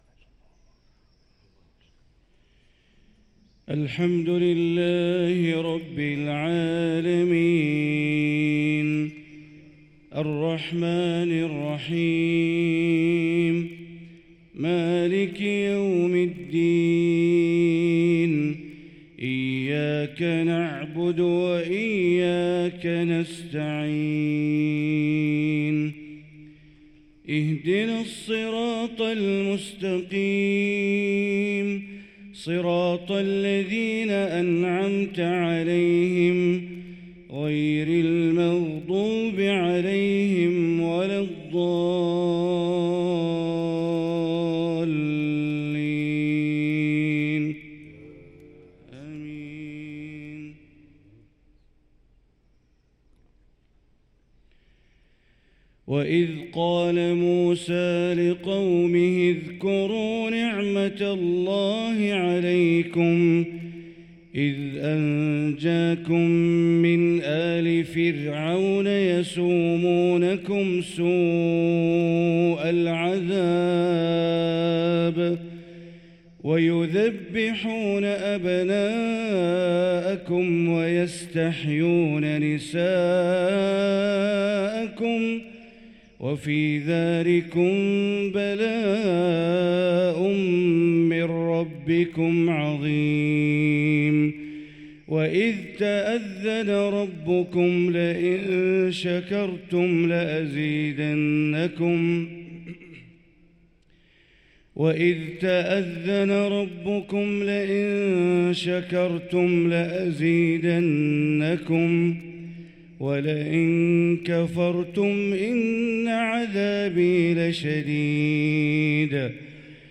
صلاة الفجر للقارئ بندر بليلة 11 رجب 1445 هـ
تِلَاوَات الْحَرَمَيْن .